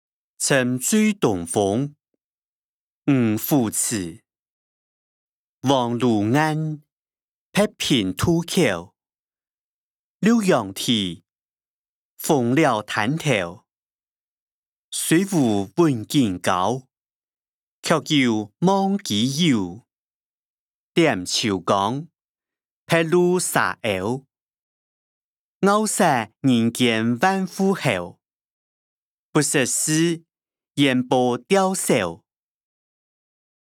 詞、曲-沉醉東風：漁父詞 音檔(四縣腔)